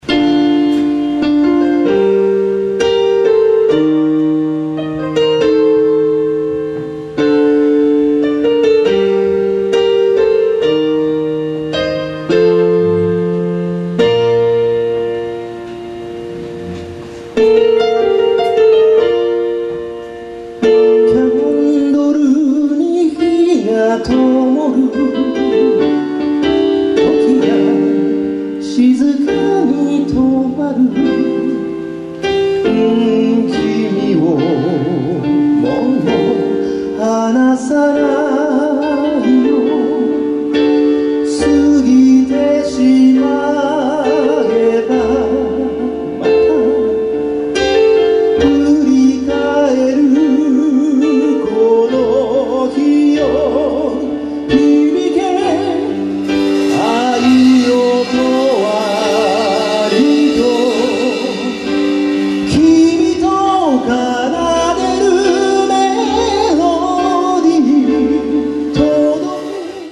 30年目の最後のライブです。
guitar,keybords,chorus
bass,chorus
drums